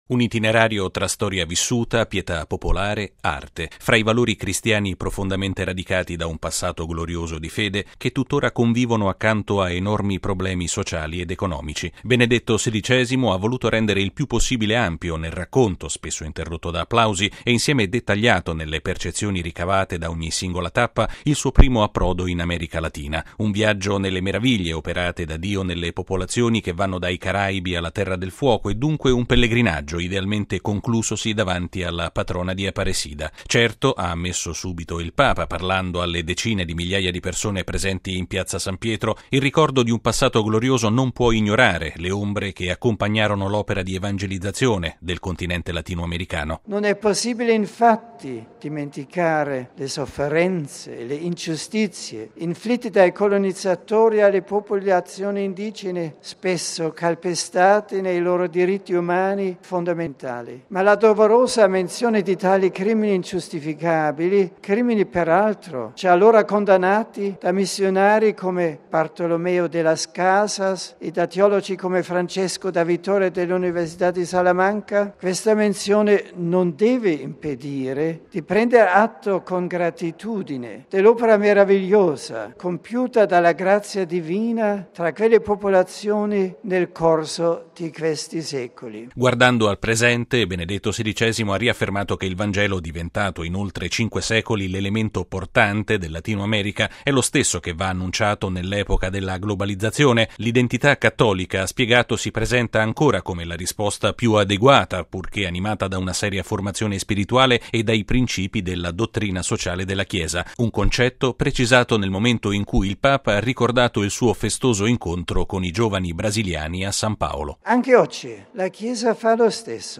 Benedetto XVI ha voluto rendere il più possibile ampio nel racconto - spesso interrotto da applausi - e insieme dettagliato nelle percezioni ricavate da ogni singola tappa, il suo primo approdo in America Latina.